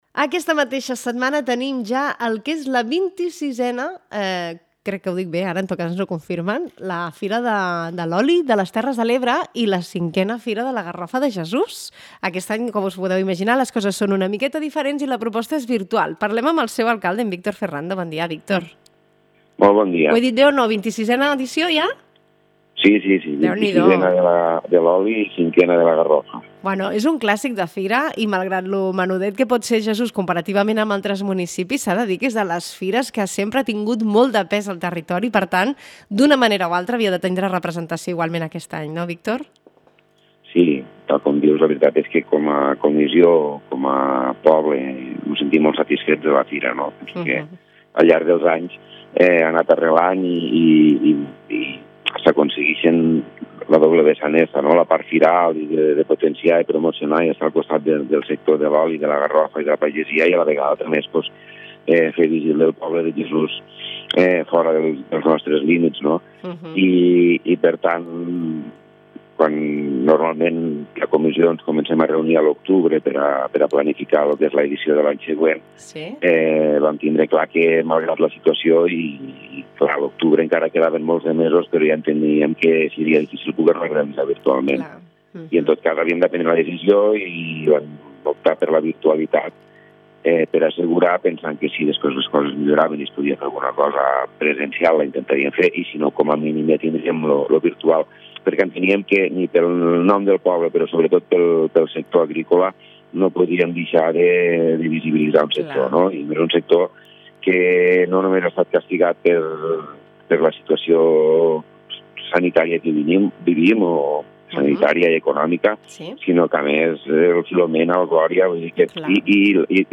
La XXVI Fira de l’Oli i V Fira de la Garrofa de Jesús tindrà lloc del 25 de febrer a l’1 de març, i enguany en un format virtual per la Covid-19. Ens en parla l’alcalde de Jesús, Víctor Ferrando.